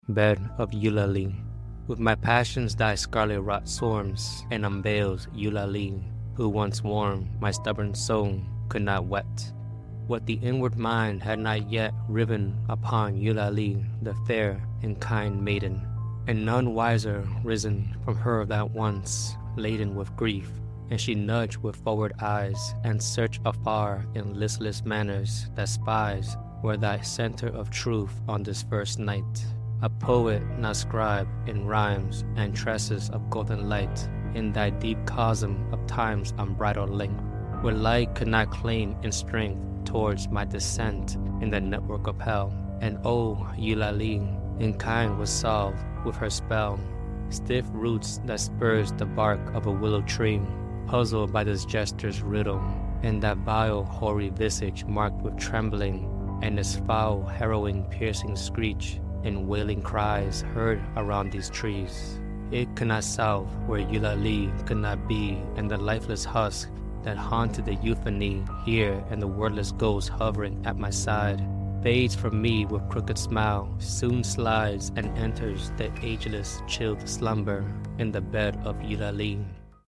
Through vivid imagery and a lyrical narrative, this poetry reading unveils the depths of a soul torn between passion and despair. Eulalie, a figure both enchanting and haunting, becomes the focal point of longing and loss, leading the poet through a dark journey of reflection and shadow work.